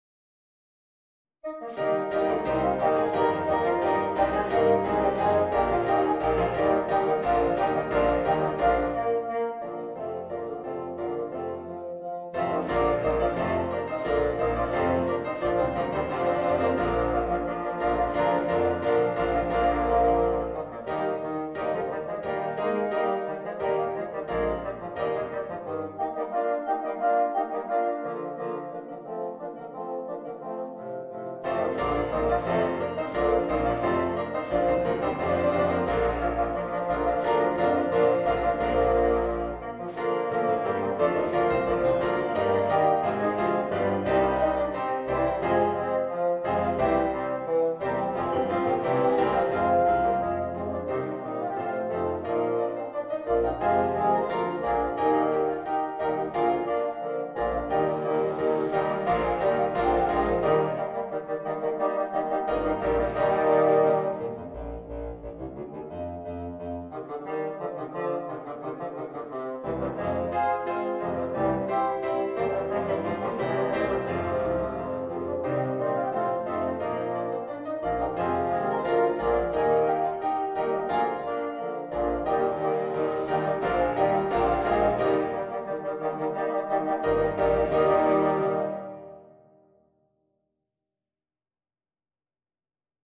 Voicing: 8 Bassoons